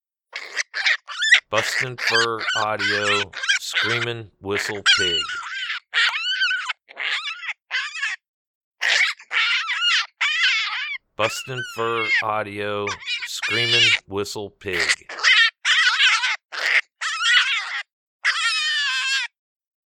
Juvenile Groundhog in distress.
BFA Screamin Whistle Pig Sample.mp3